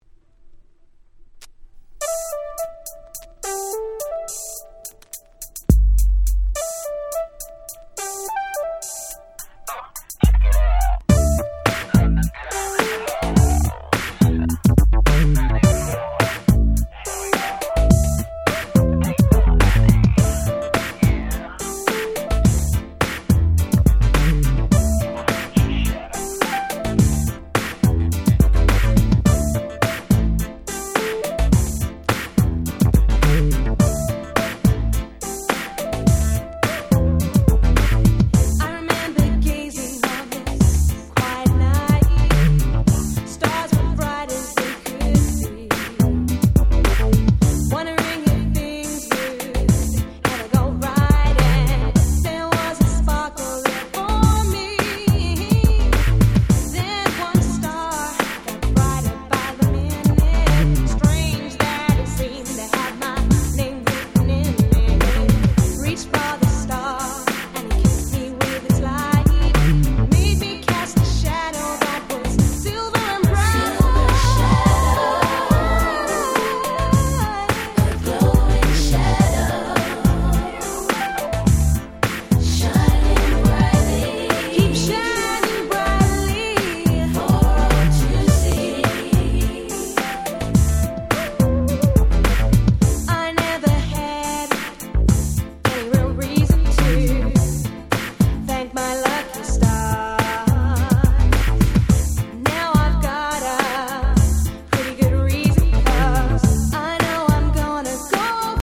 94' Super Nice Cover UK R&B !!
程良いビーヒャラ感もありつつしっかりしたBeatでフロア映えもバッチリ！！